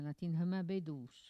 Maraîchin
locutions vernaculaires